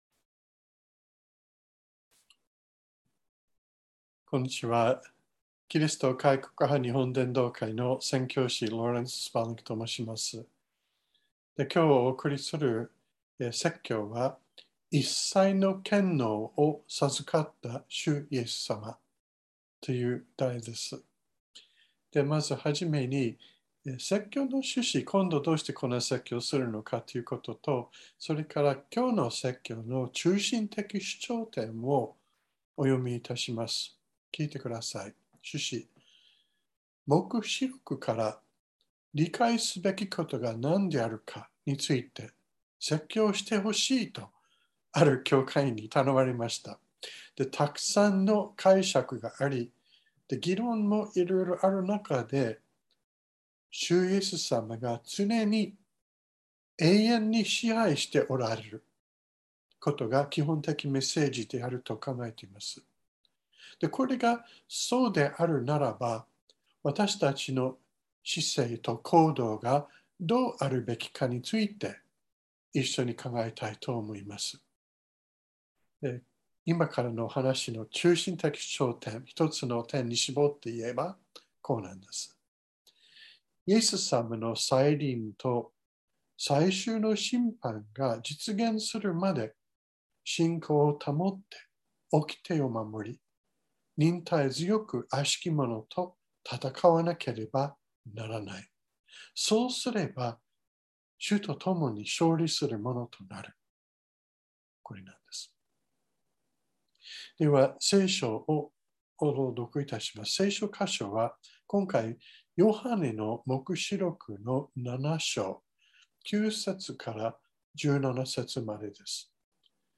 2022年07月24日朝の礼拝「一切の権能を授かった主イエス様」川越教会
説教アーカイブ。
音声ファイル 礼拝説教を録音した音声ファイルを公開しています。